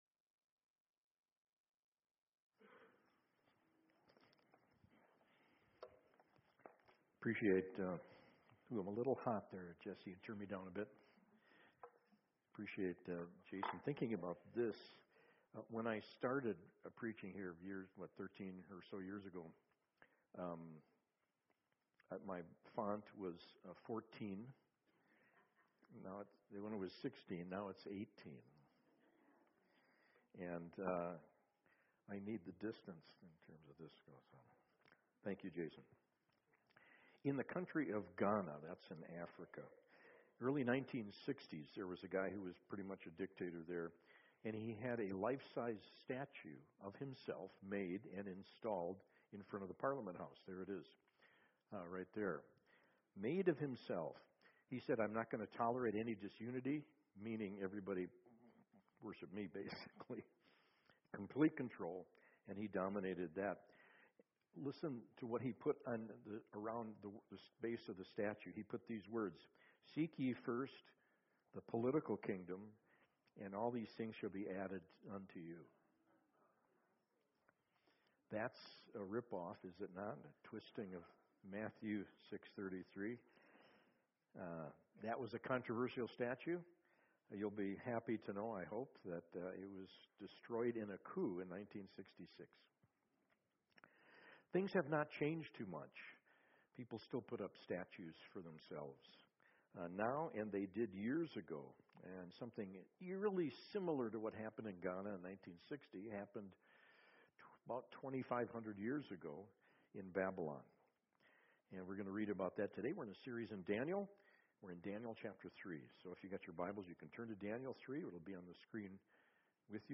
SERMONS Daniel Series #3 “To What Do You Bow Down?”